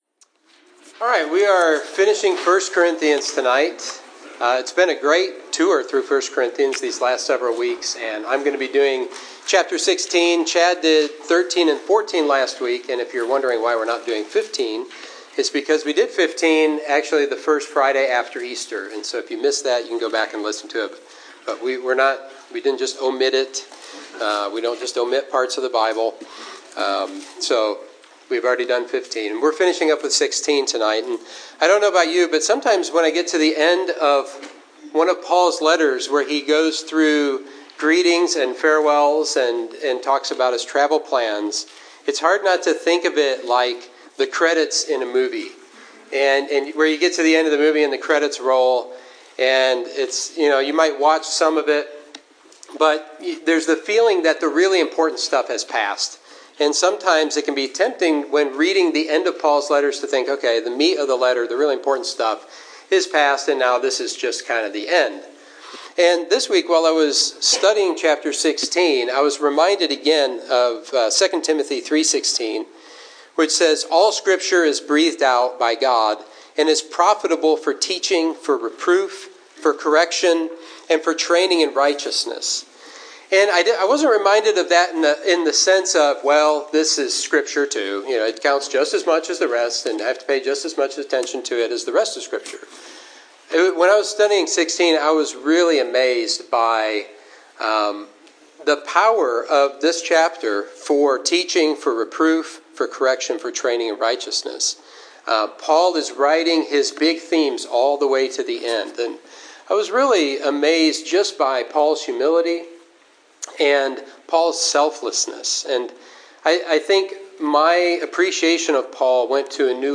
Sermon 5/28: 1 Corinthians 16: Love Is the Last Word – Trinity Christian Fellowship